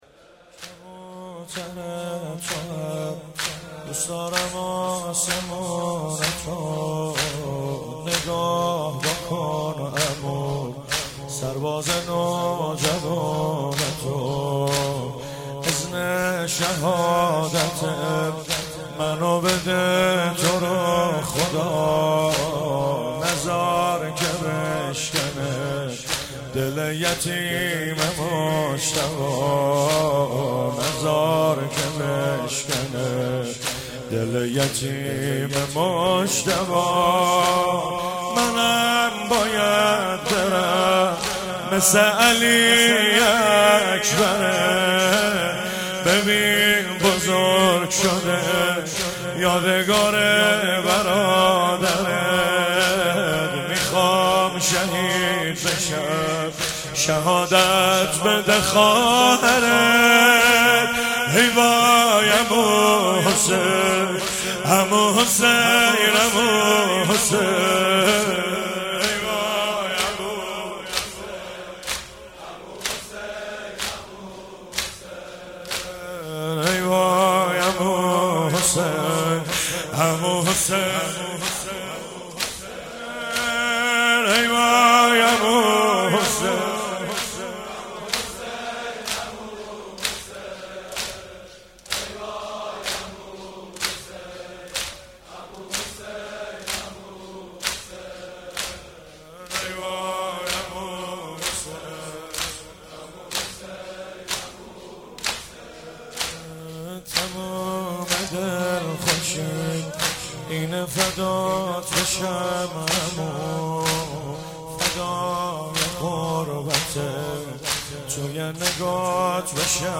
شب ششم محرم97 - زمینه - کبوتر توام دوست دارم